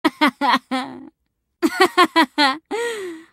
Play Risada Quinn - SoundBoardGuy
dublagens-lol-br-dublagem-da-quinn-mp3cut.mp3